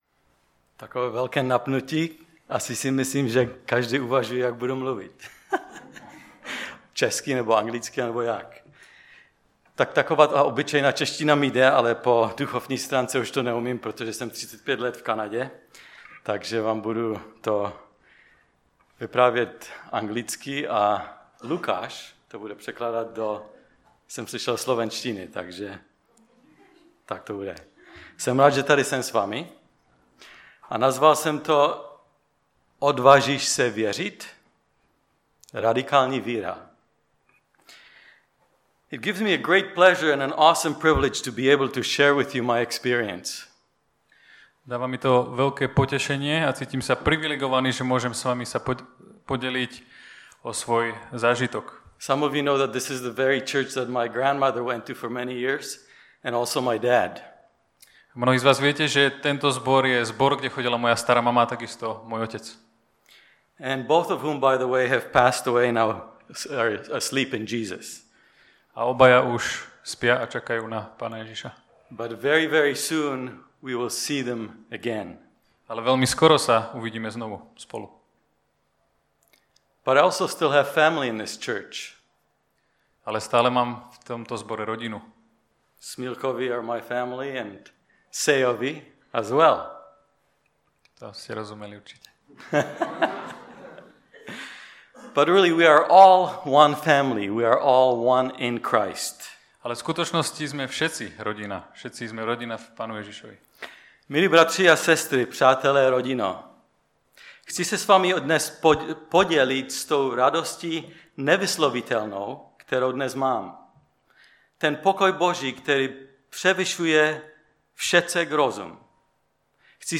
Série: Odvážíš se věřit? Typ Služby: Přednáška Preacher